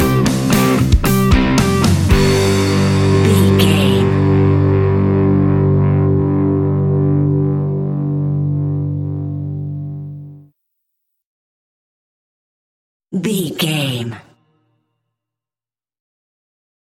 Epic / Action
Fast paced
Ionian/Major
hard rock
blues rock
distortion
rock guitars
Rock Bass
Rock Drums
heavy drums
distorted guitars
hammond organ